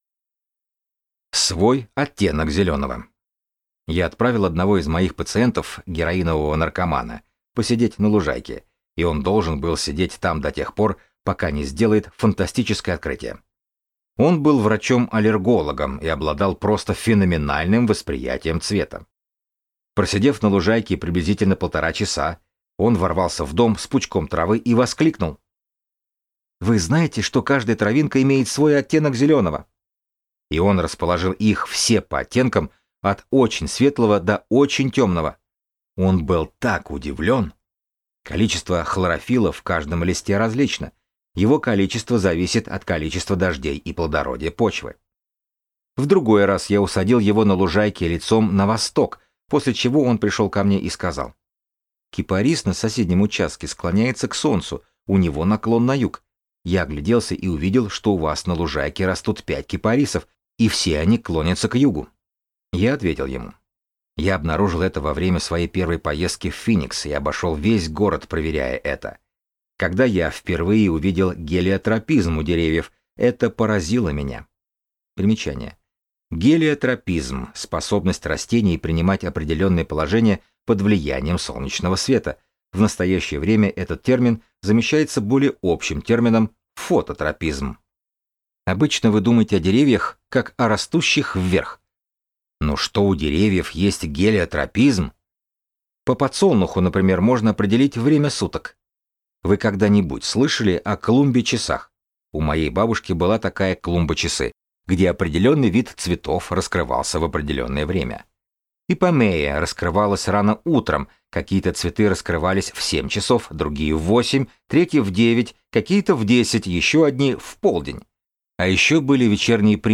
Аудиокнига Наблюдайте: замечайте различия | Библиотека аудиокниг